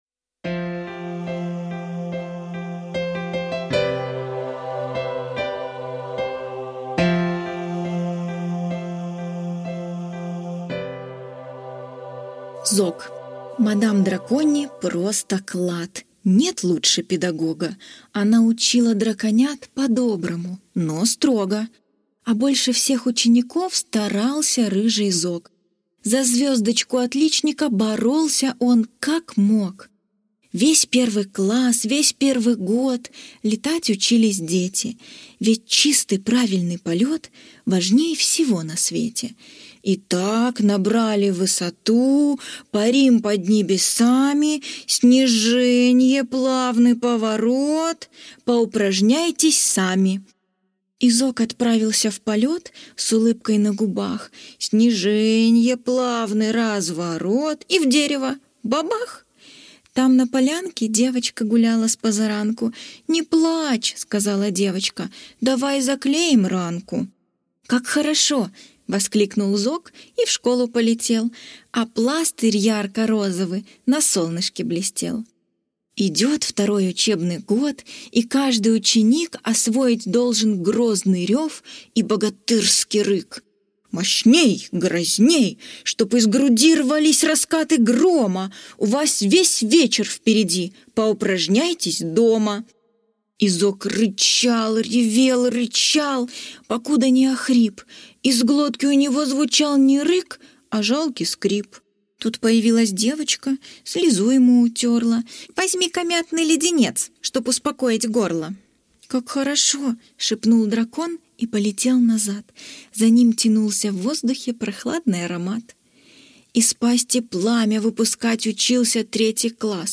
Аудиосказка «Зог» – Дональдсон Д.
Аудиосказка «Зог»